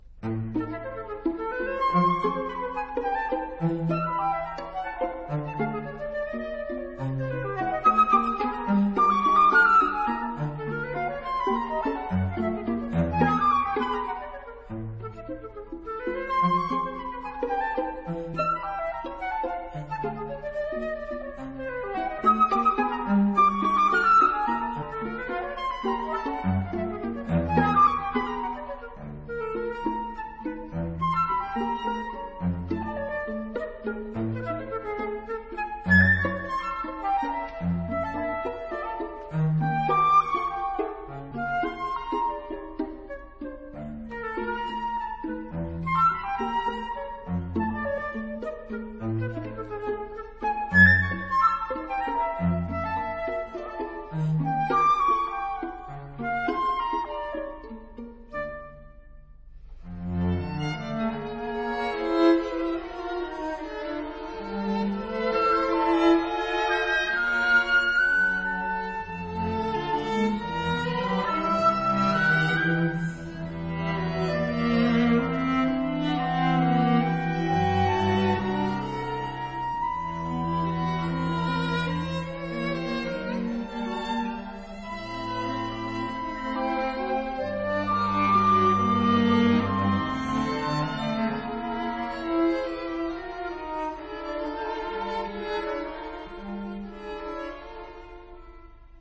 還有長笛與弦樂的合奏（試聽四）。
她呈現了一種百年累積後，又開了小花的香味。